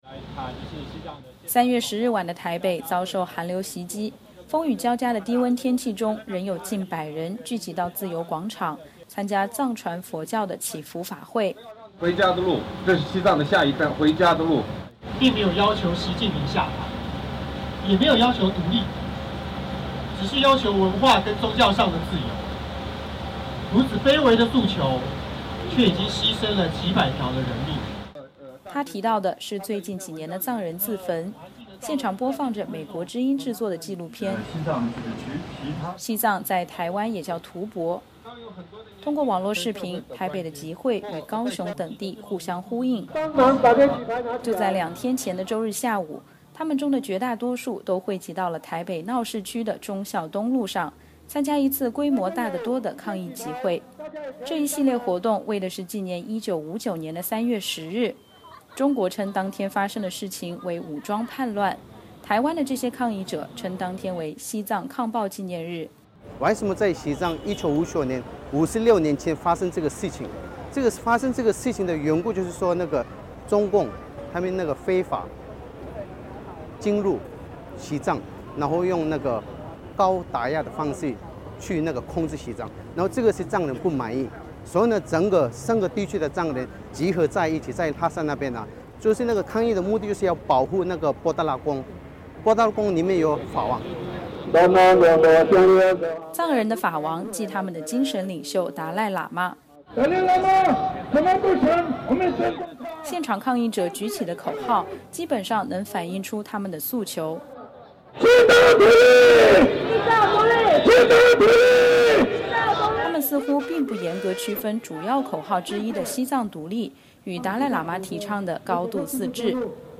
3月10日晚的台北遭受寒流袭击，风雨交加的低温天气中仍有近百人聚集到自由广场，参加藏传佛教的祈福法会。
从现场人的讲话大致可以听出这次集会的主题。